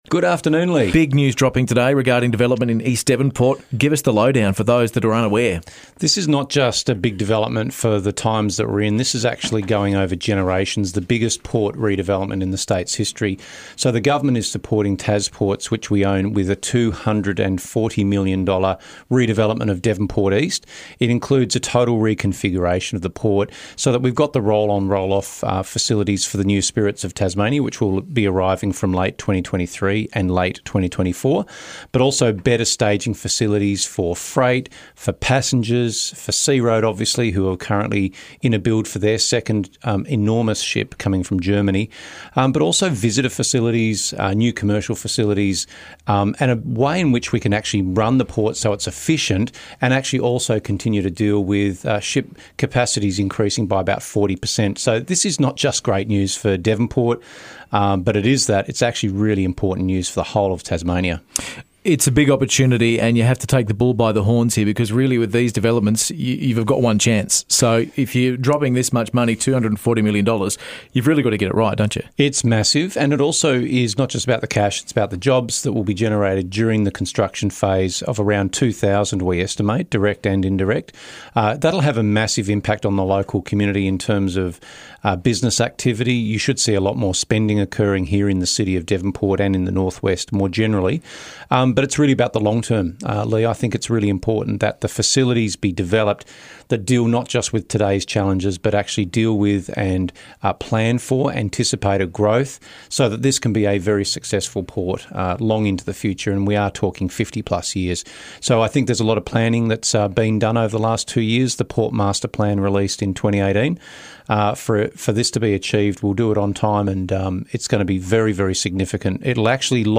Minister for Infrastructure and Transport, Michael Ferguson, stopped by with details on a $240m investment into the Port of Devonport.